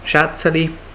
Solothurnisch Schätzeli Gsw-solothurnisch-wasseramt-Schätzeli.ogg
[ˈʒ̊ætsəli](info) ‚Schätzli‘
Gsw-solothurnisch-wasseramt-Schätzeli.ogg